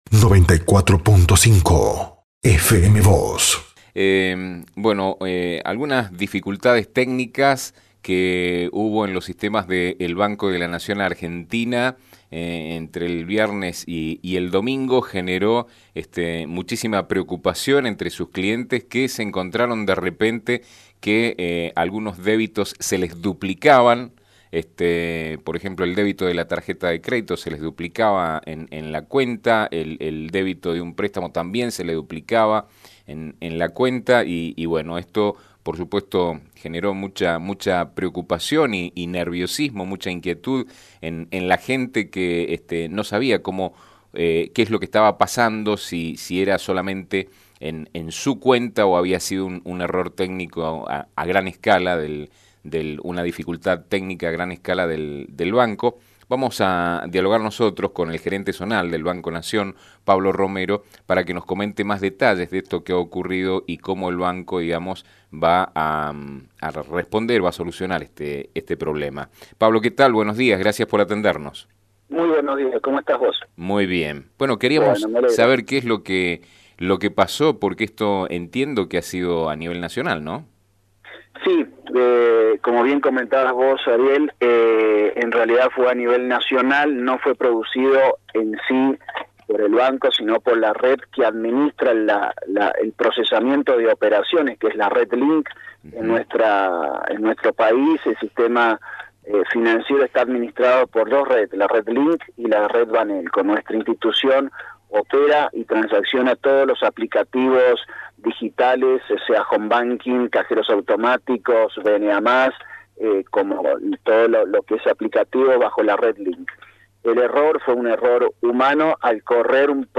Se trató de un error humano por la duplicación del proceso, señalaron ayer en Fm Vos (94.5).